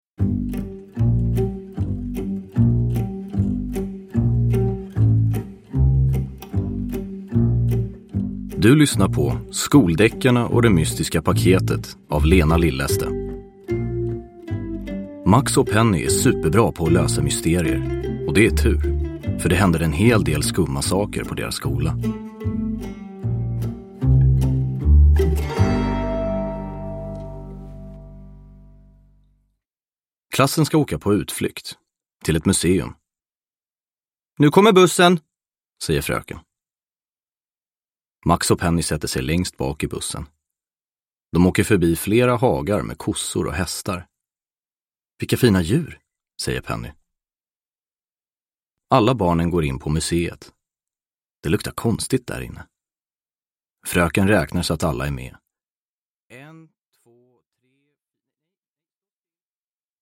Det mystiska paketet – Ljudbok – Laddas ner